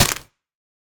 Minecraft Version Minecraft Version latest Latest Release | Latest Snapshot latest / assets / minecraft / sounds / block / mangrove_roots / step5.ogg Compare With Compare With Latest Release | Latest Snapshot